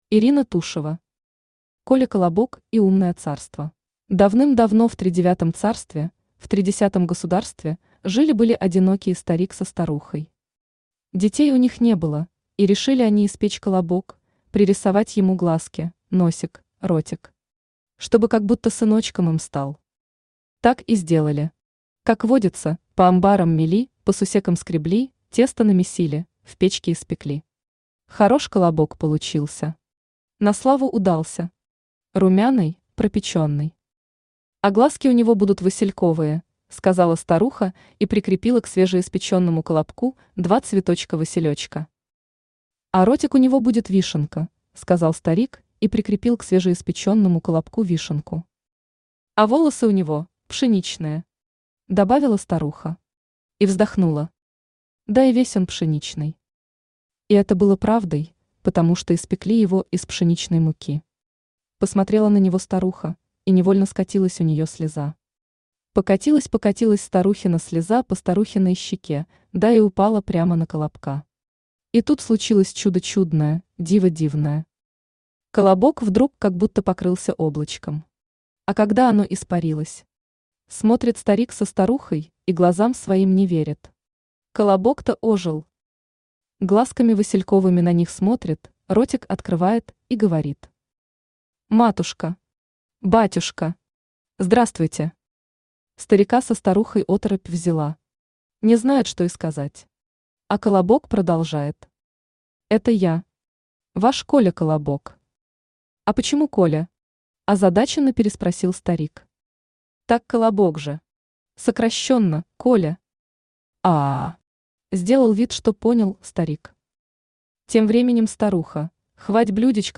Аудиокнига Коля-колобок и Умное царство